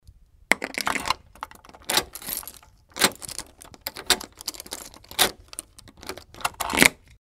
Звук ключей в замке открытие